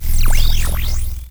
sci-fi_electric_pulse_hum_09.wav